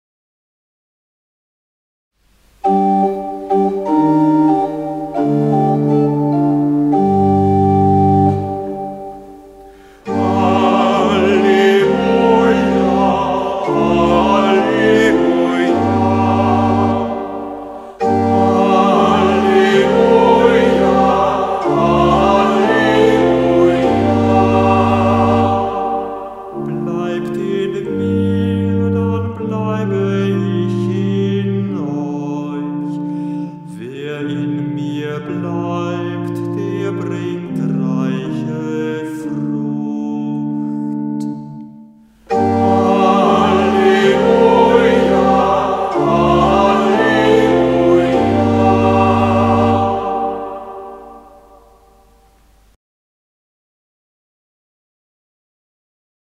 Halleluja aus dem Gotteslob